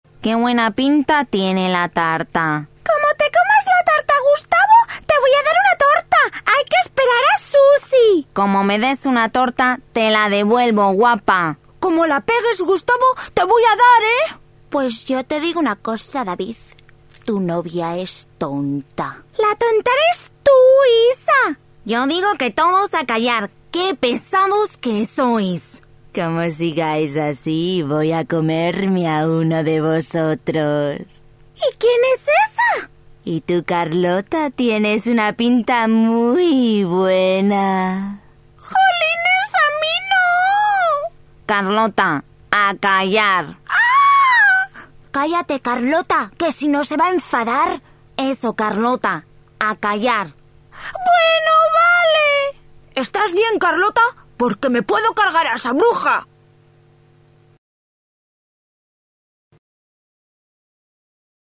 Experienced Castilian actress with a talent for character voices.